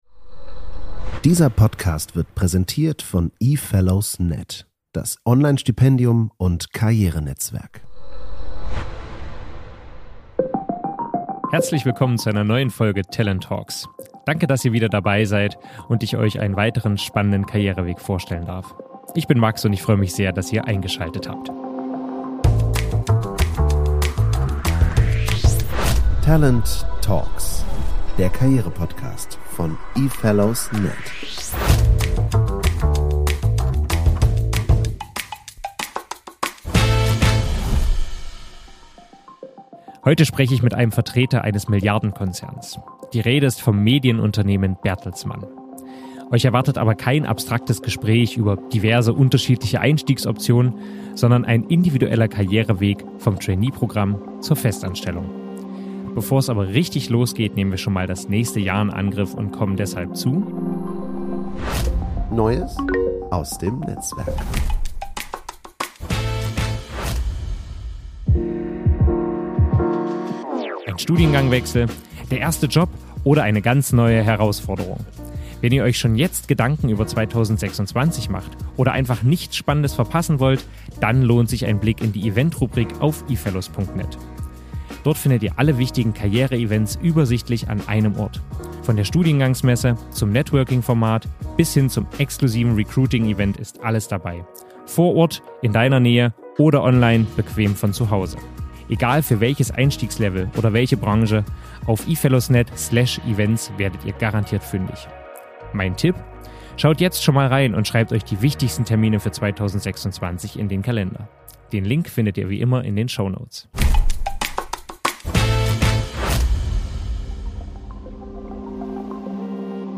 Im Fokus stehen die drei Tracks Business, Creative Management und Data Science, die Trainees unterschiedliche Einblicke in das Unternehmen ermöglichen. Im Gespräch geht es außerdem ums Netzwerken im Konzern, internationale Erfahrungen, Entscheidungsspielräume und die Frage, wie man Karriere in einem großen Unternehmen aktiv selbst gestaltet.